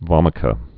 (vŏmĭ-kə)